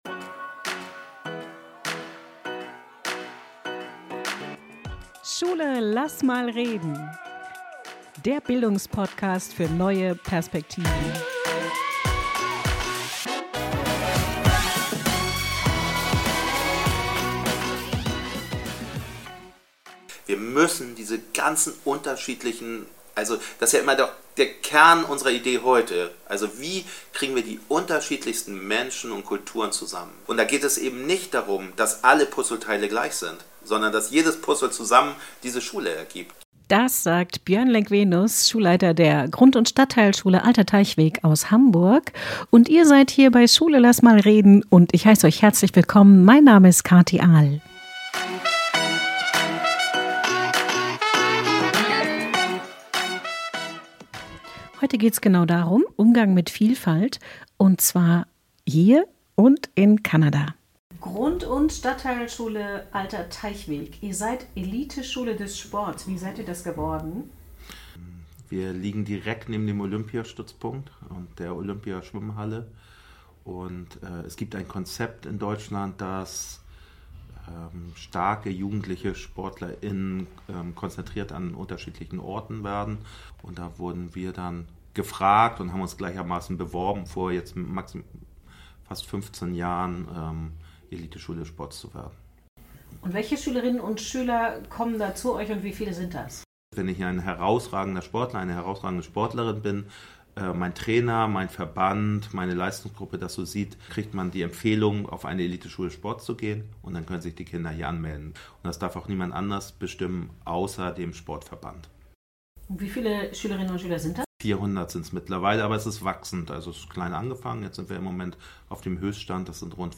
Folge 7: Pädagogische Provokationen in Kanada und ausgerechnet Curling Interview mit kanadischen Kolleginnen